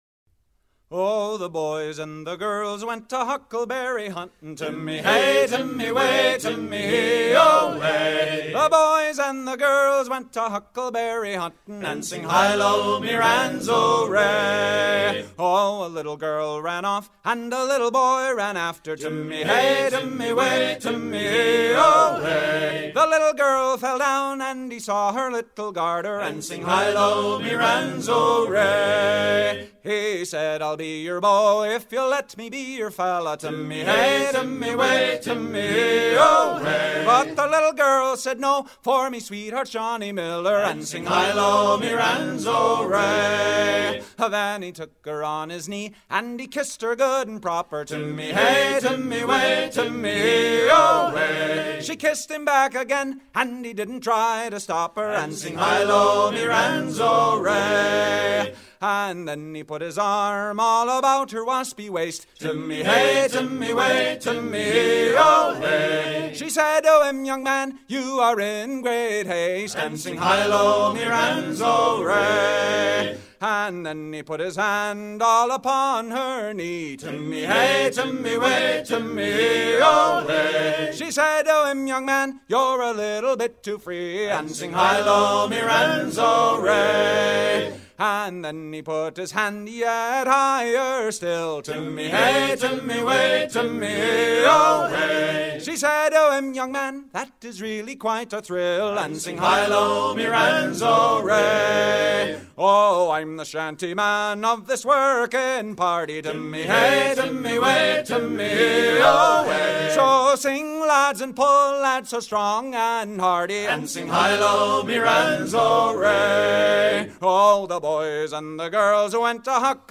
gestuel : à virer au guindeau ; gestuel : à pomper
Pièce musicale éditée